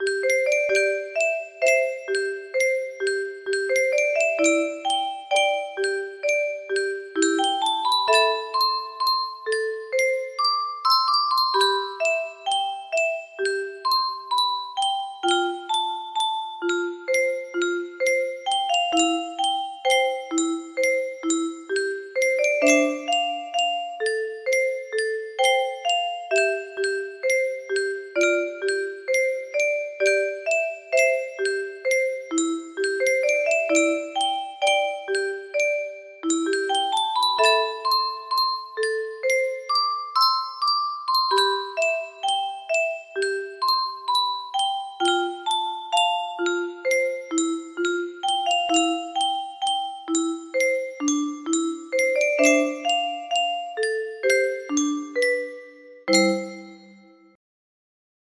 Grand Illusions 30 music boxes More